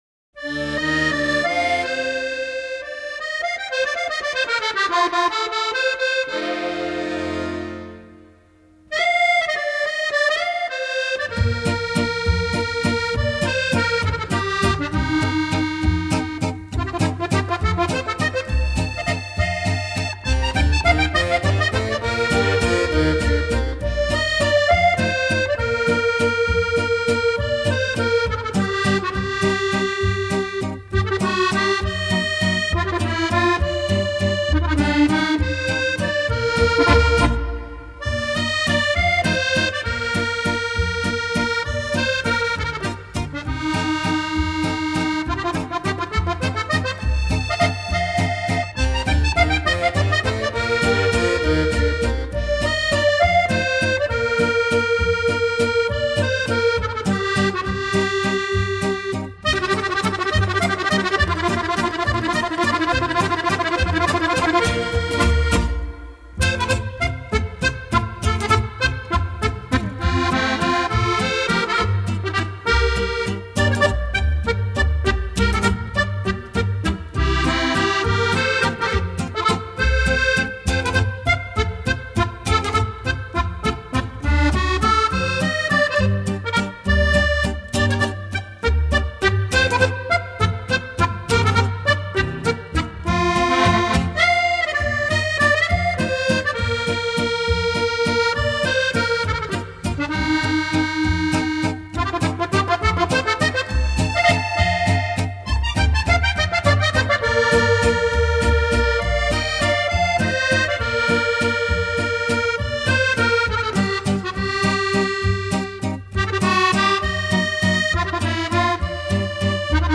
Gran Valzer